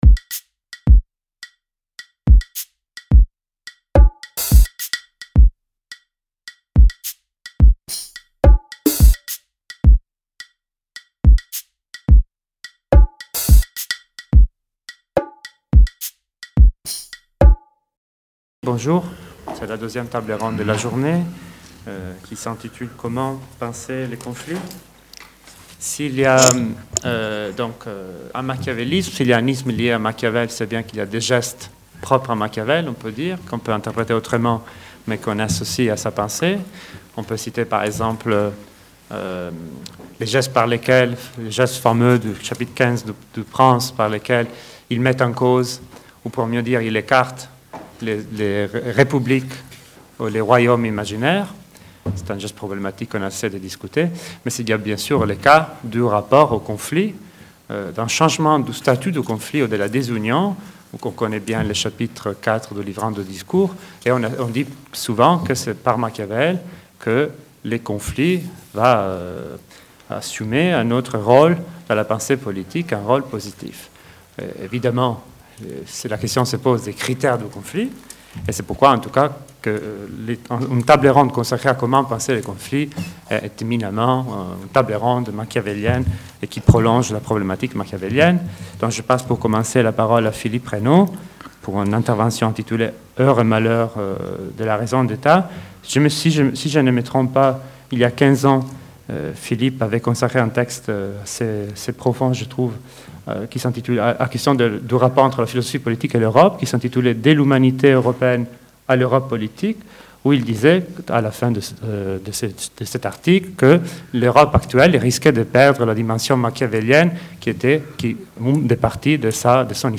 Journée d’études organisée par le CESPRA et la Société des Amis de Raymond Aron
Comment comprendre aujourd'hui, et à la lumière de l'histoire, le machiavélisme et la politique entendue comme une technique, sinon immorale, du moins amorale ? 2e table ronde : Comment penser les conflits ?